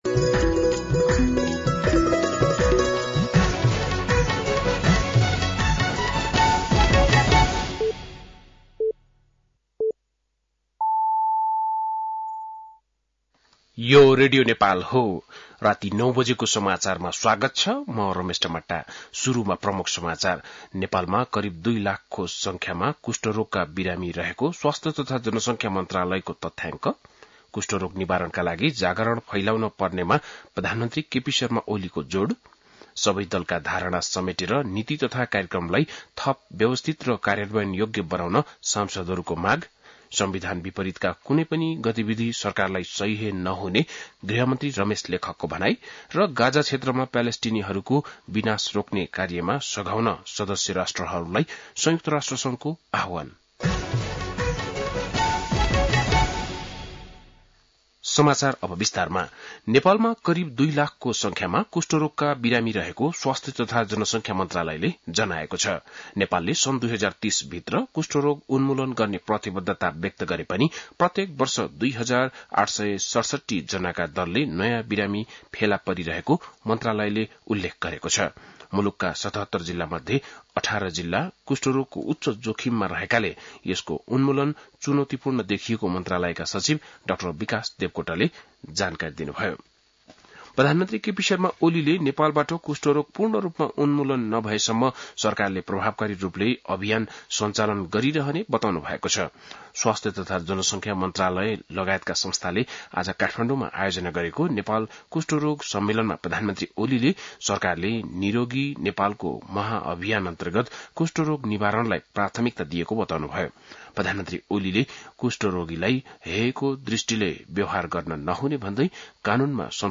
बेलुकी ९ बजेको नेपाली समाचार : २५ वैशाख , २०८२
9-PM-Nepali-NEWS-.mp3